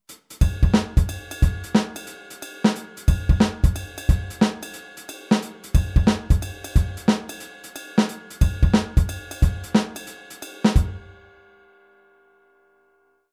Shuffle-Offbeat? Eigentlich nur seitenverdreht?